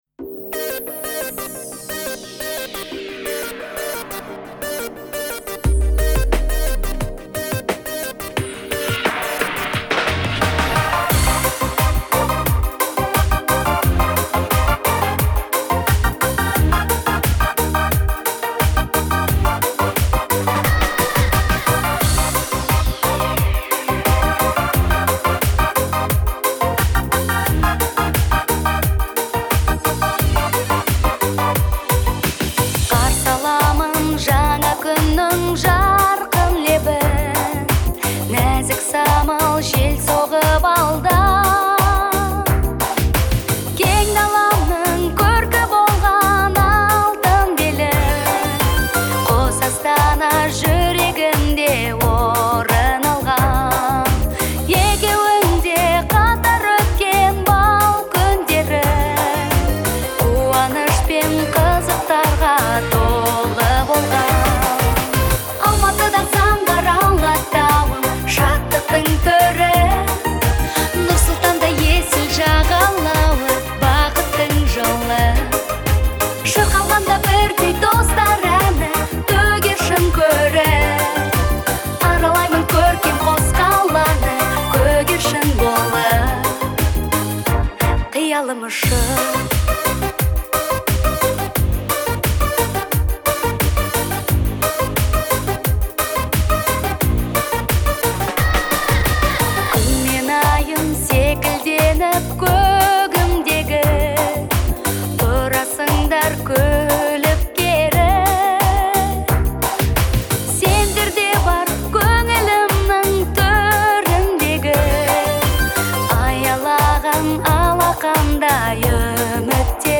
это яркая и мелодичная песня в жанре поп